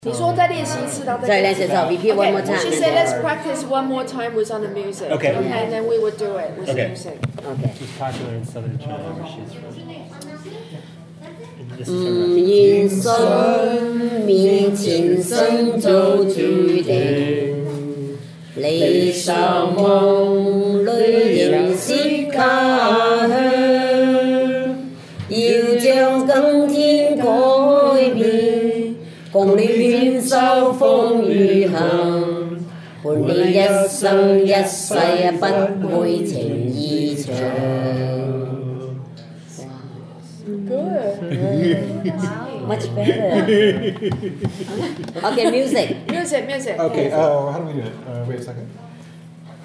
Each week, members of the choral study group assemble to learn a thematically related archival piece of vocal music by ear.
Hidden Gem Rehearsal